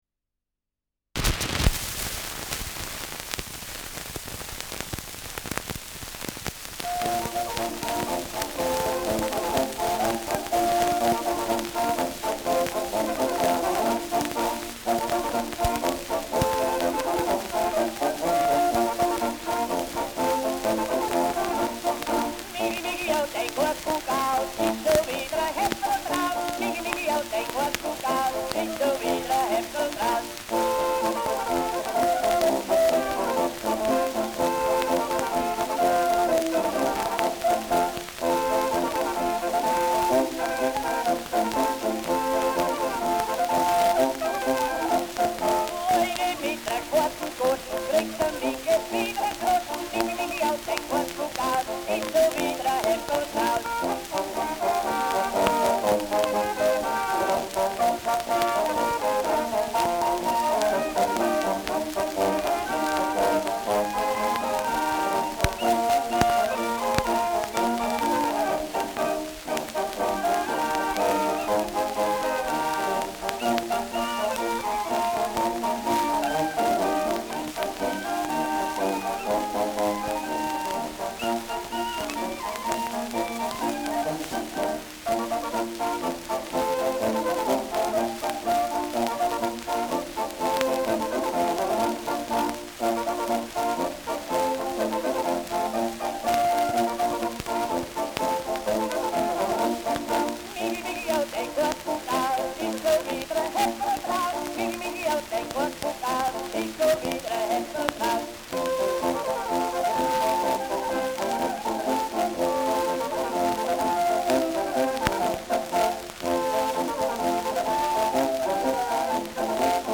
Schellackplatte
Starkes Grundrauschen : Durchgehend leichtes bis starkes Knacken : Keine passende rpm-Geschwindigkeit
Mit Mäh-Geschrei am Ende.